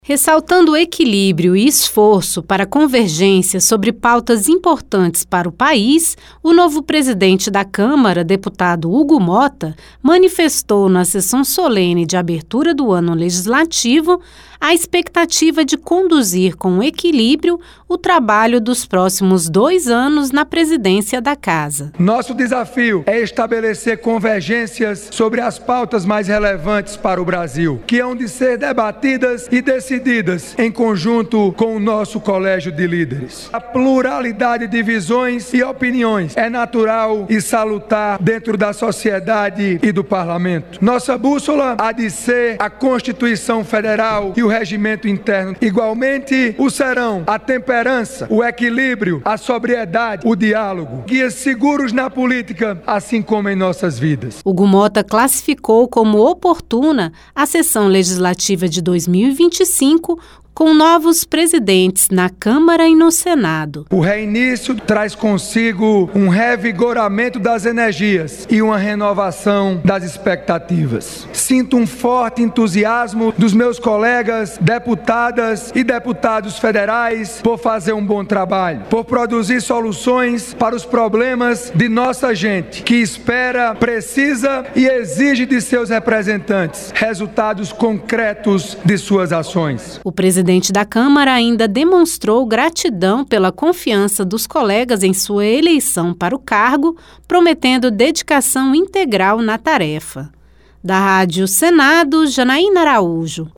O novo presidente da Câmara dos Deputados, Hugo Motta, afirmou que se empenhará em promover o diálogo e a construção de consensos em temas fundamentais para o Brasil debatidos no Parlamento. Em seu pronunciamento durante a sessão solene de abertura dos trabalhos do Poder Legislativo, o deputado destacou a importância de respeitar a Constituição e as normas internas do Congresso, além de buscar equilíbrio e convergência entre diferentes visões e opiniões.
Pronunciamento